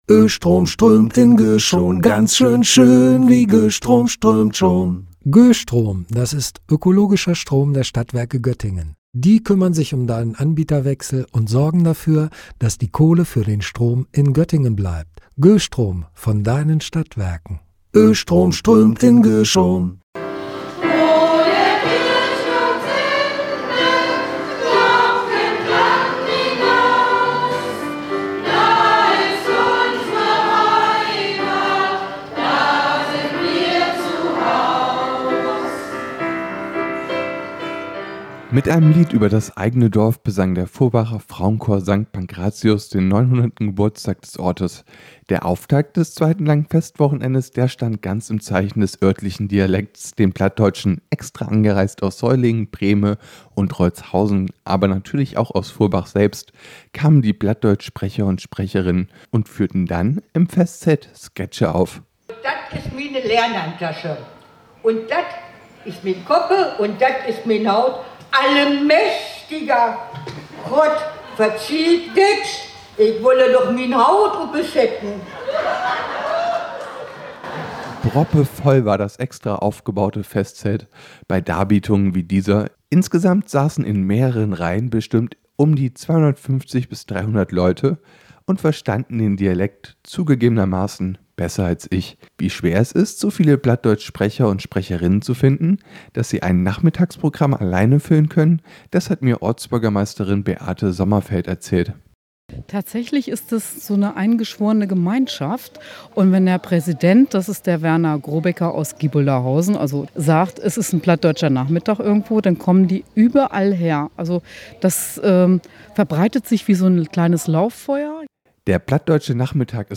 Zum Start gab es traditionelle Musik und örtliche Kultur.